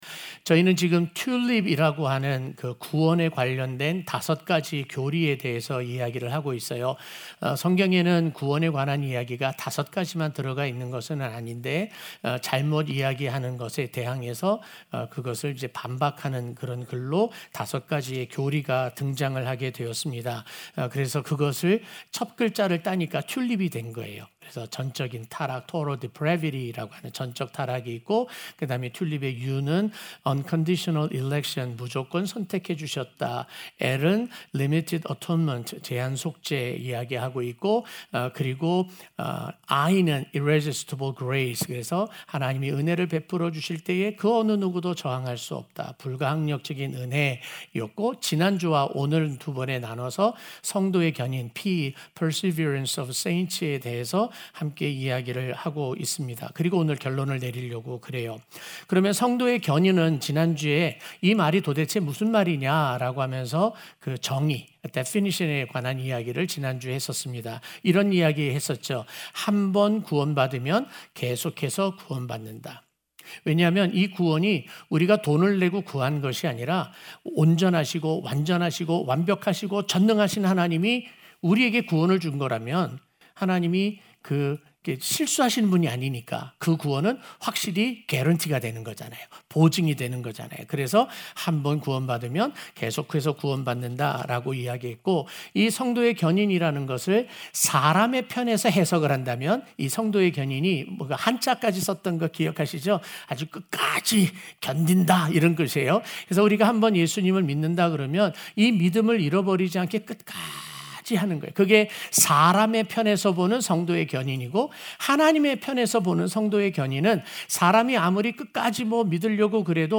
05-B-Perseverance-of-the-saints-2-Sunday-Morning.mp3